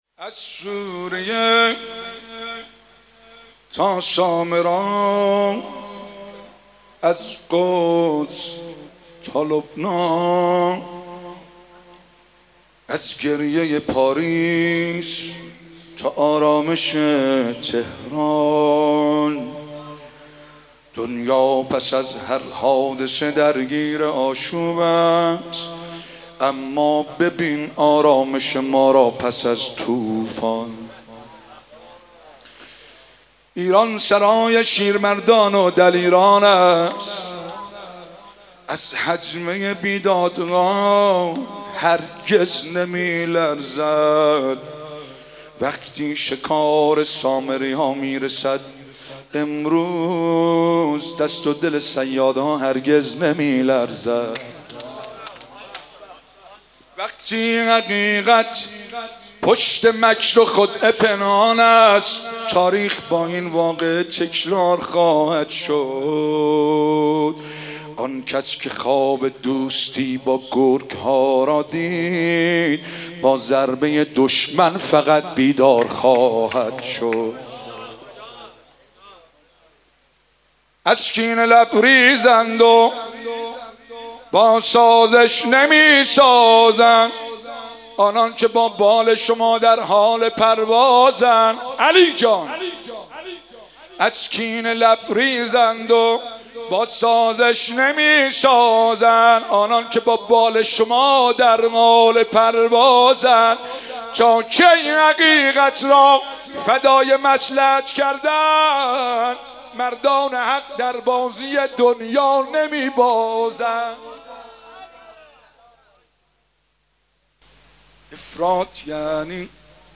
رمضان